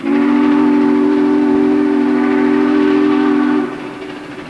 Whistle.wav